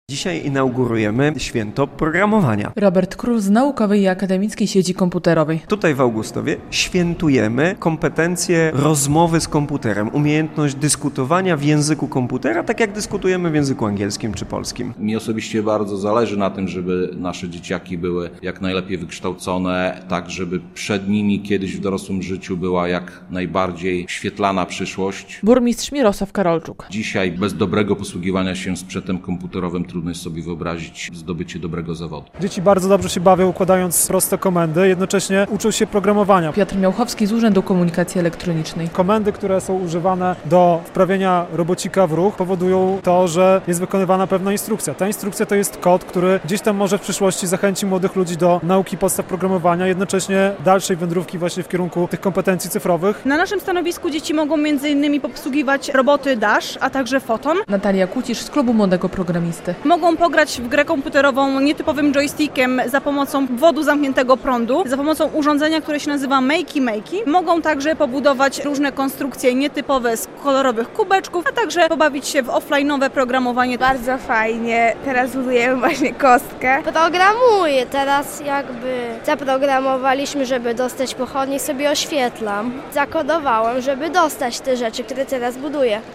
CodeWeek 2023 w Augustowie - relacja